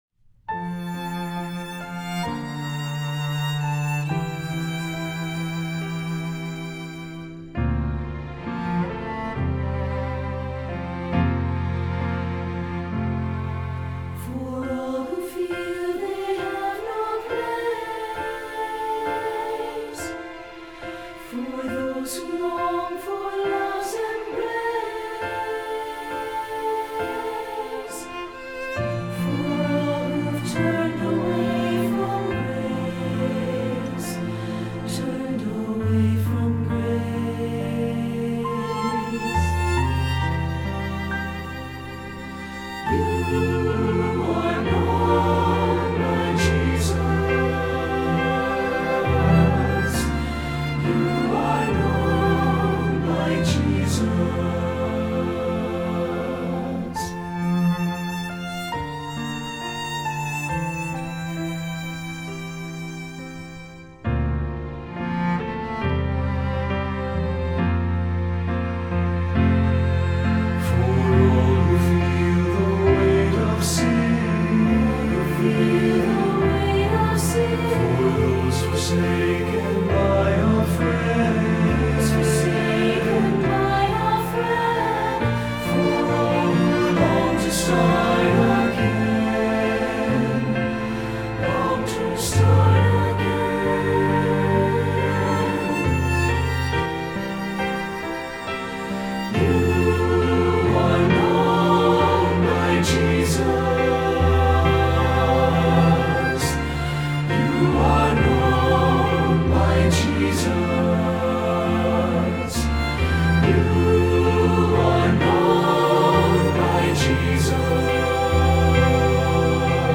Feuillet pour Chant/vocal/choeur - SATB